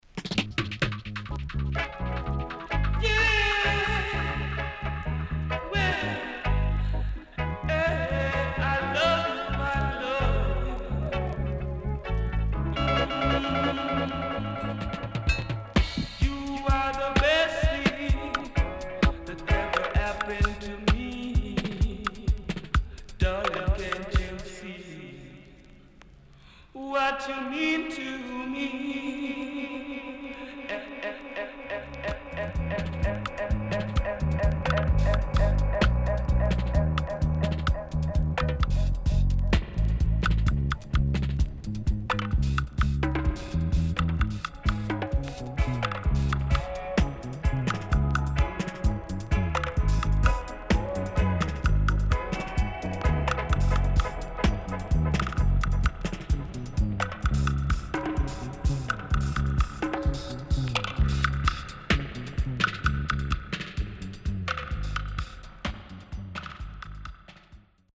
CONDITION SIDE A:VG+〜EX-
Killer & Dubwise
SIDE A:盤質は良好です。